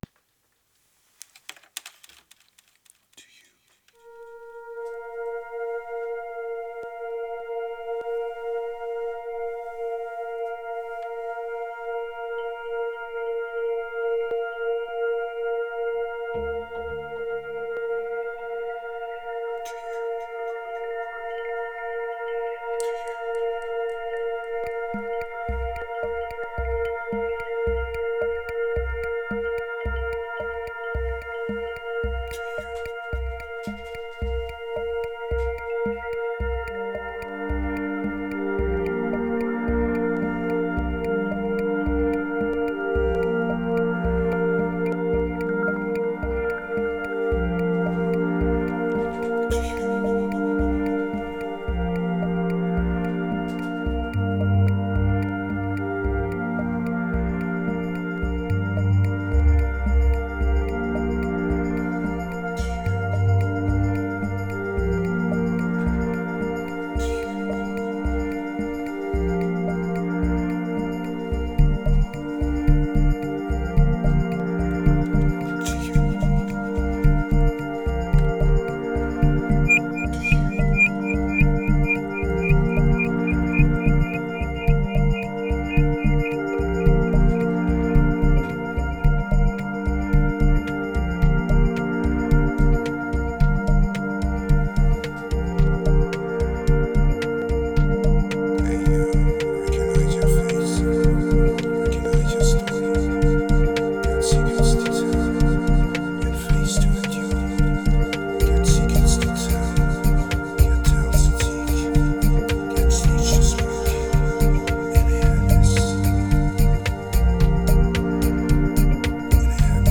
2266📈 - 67%🤔 - 110BPM🔊 - 2015-02-08📅 - 373🌟